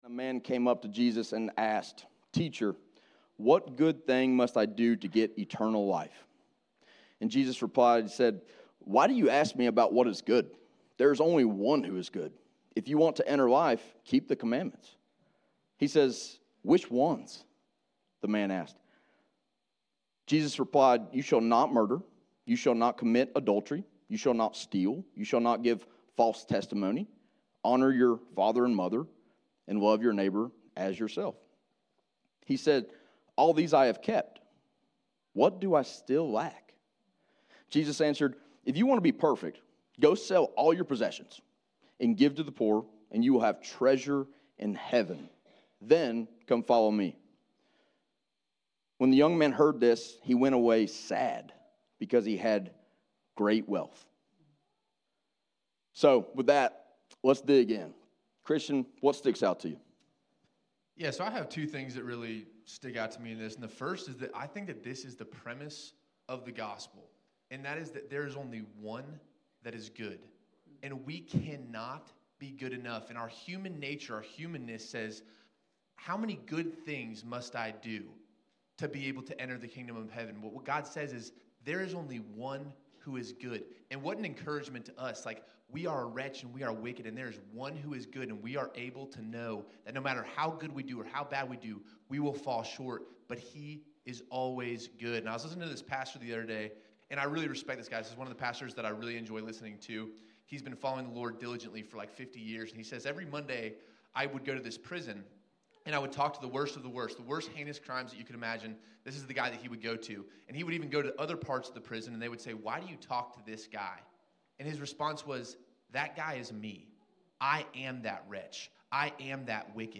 This week, three of our Vertical Church elders led us in a panel discussing Mark 1:16-20 and Matthew 19:16-22! They talked about what it looks like for us to live as willing disciples of Jesus.